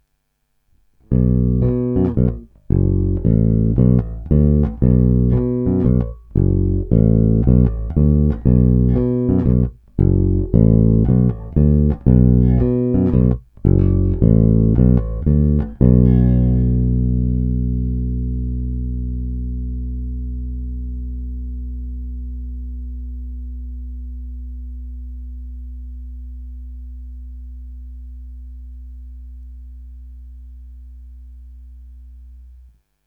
Zvonivý, vrnivý, pevný.
Není-li uvedeno jinak, následující nahrávky jsou provedeny rovnou do zvukovky a kromě normalizace ponechány bez dodatečných úprav.
Hráno nad snímačem